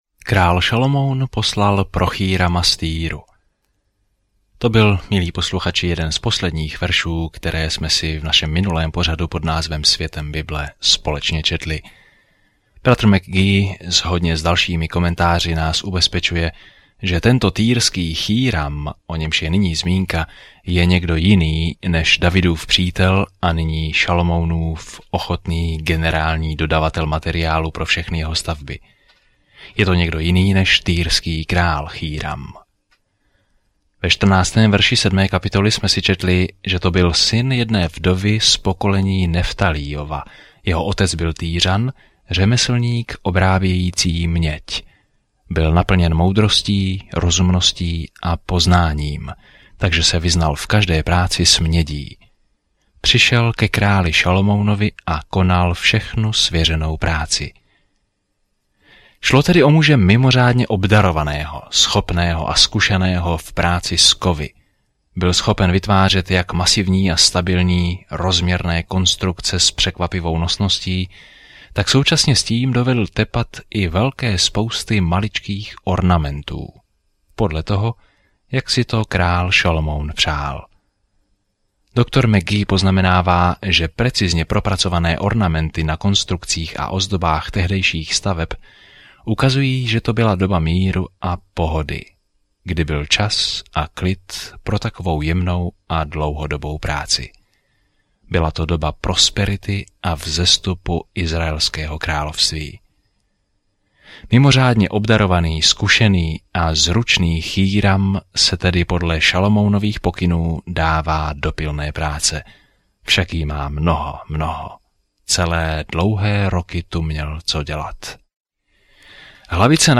Písmo 1 Královská 7:15-51 1 Královská 8:1-13 Den 8 Začít tento plán Den 10 O tomto plánu Kniha králů pokračuje v příběhu o tom, jak izraelské království za Davida a Šalomouna vzkvétalo, ale nakonec se rozpadlo. Denně cestujte po 1. králi, poslouchejte audiostudii a čtěte vybrané verše z Božího slova.